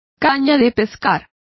Complete with pronunciation of the translation of rods.